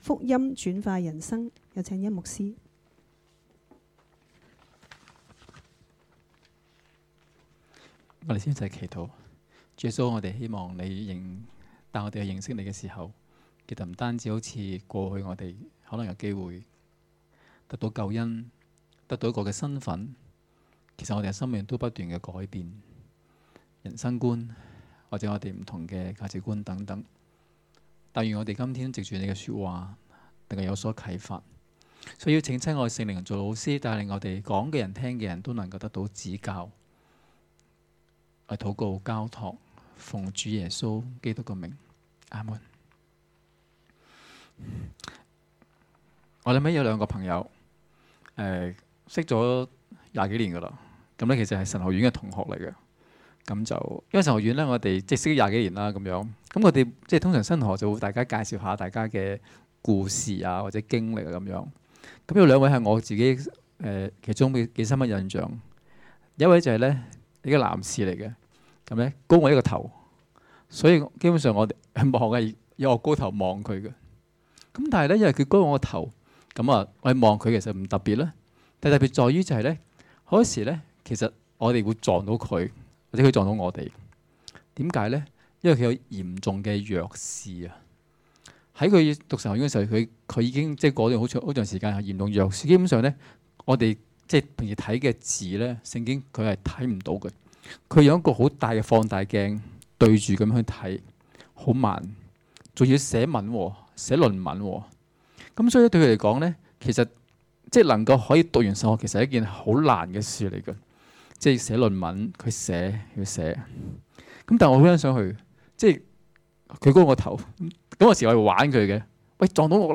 2016年11月12日及13日崇拜講道